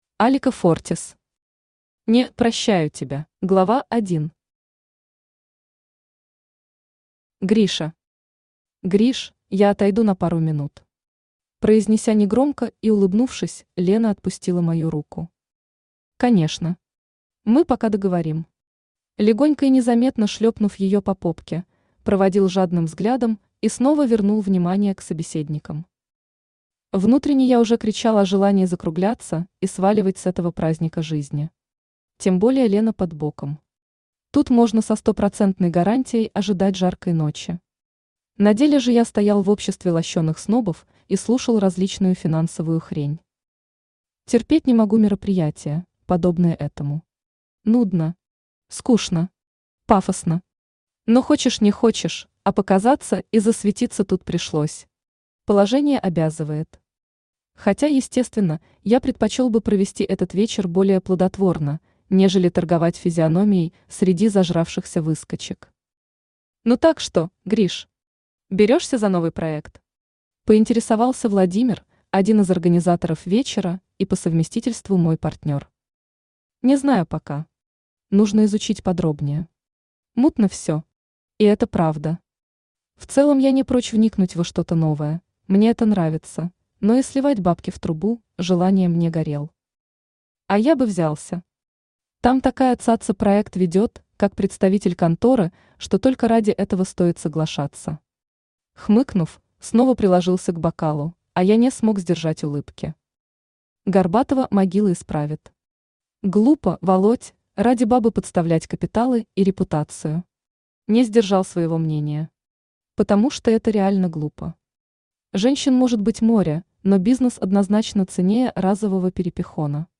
Aудиокнига (Не) прощаю тебя Автор Алика Фортис Читает аудиокнигу Авточтец ЛитРес. Прослушать и бесплатно скачать фрагмент аудиокниги